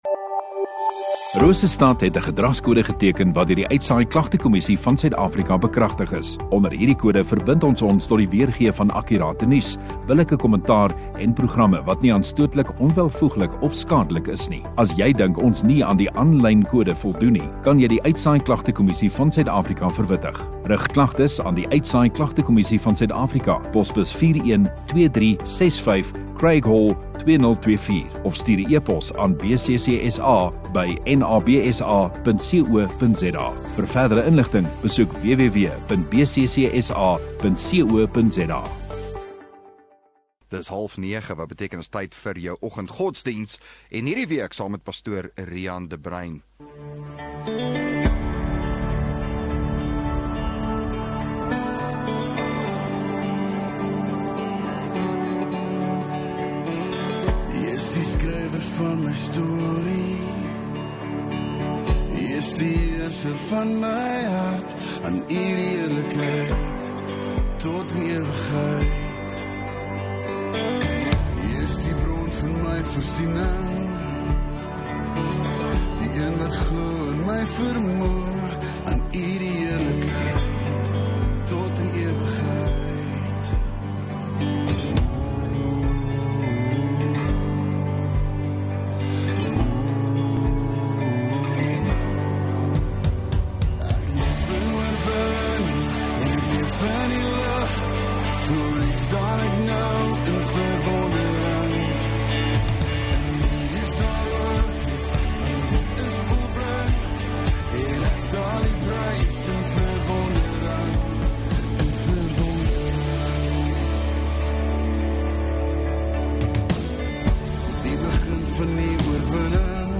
29 Jul Dinsdag Oggenddiens